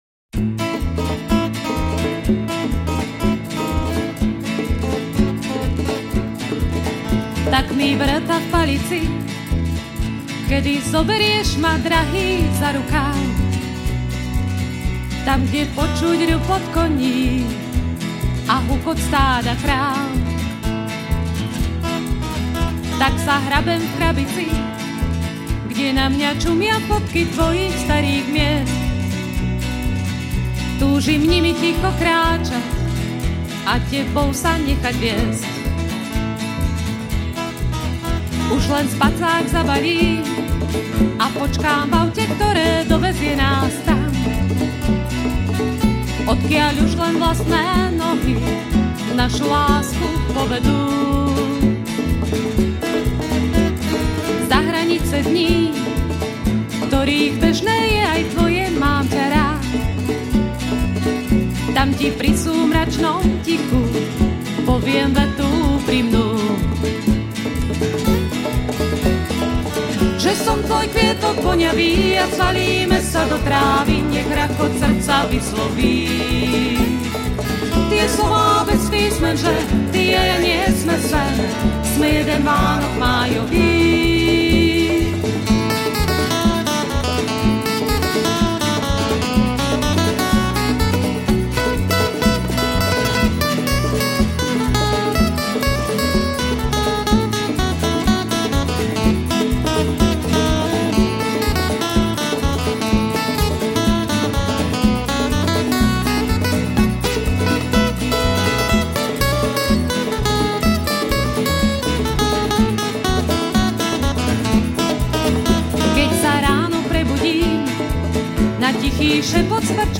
Žánr: World music/Ethno/Folk